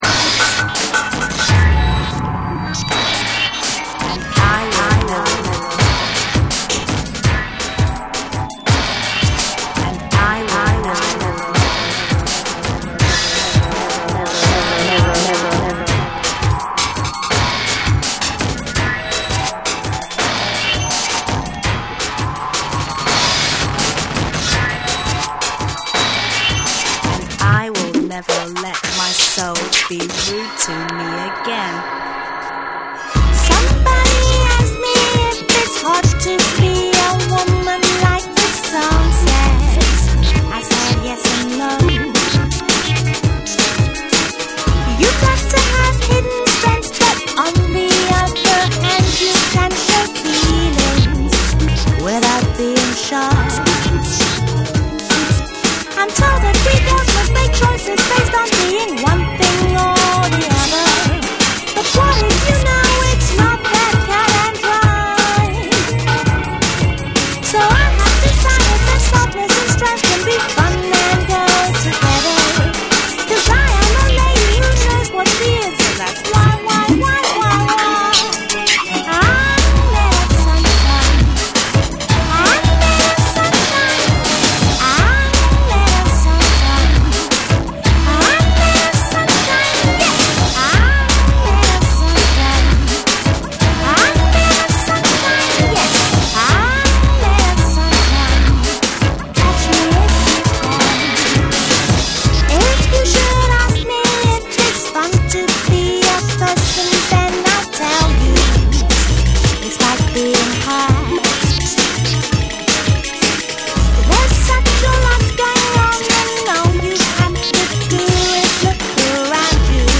BREAKBEATS / JUNGLE & DRUM'N BASS